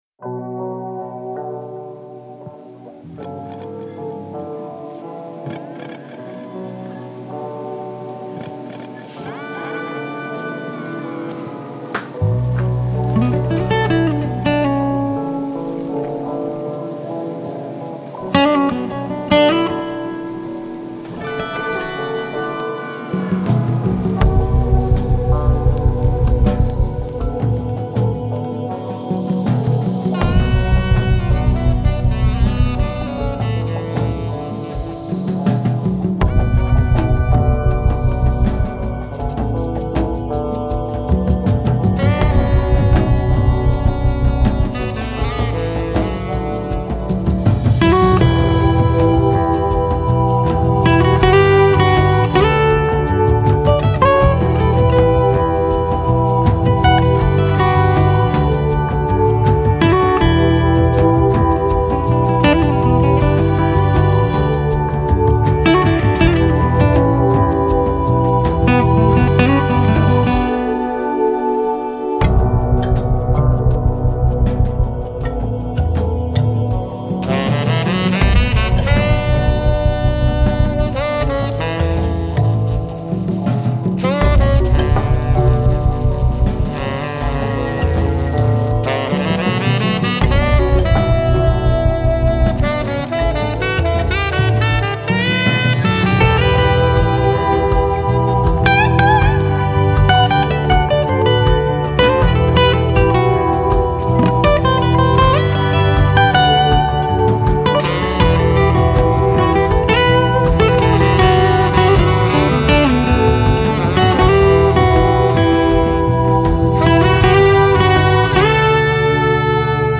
Genre : Trash Metal